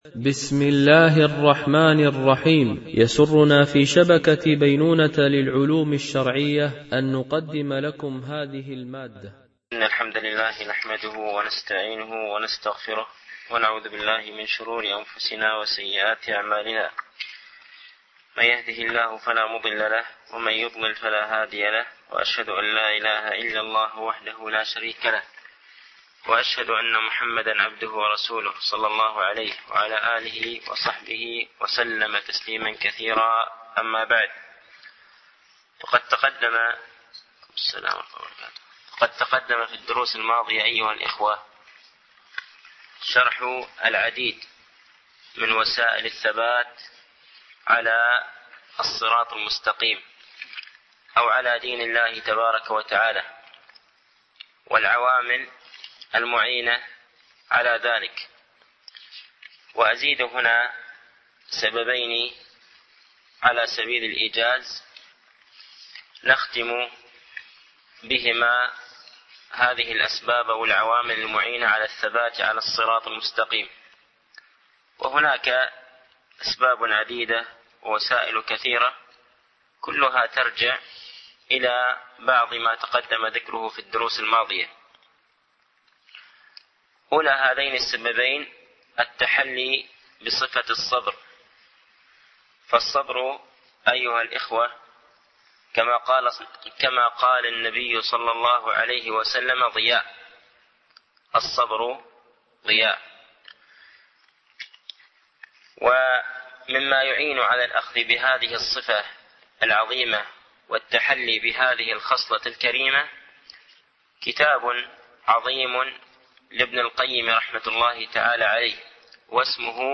شرح أعلام السنة المنشورة ـ الدرس 128 ( ما ضد السنة ؟ )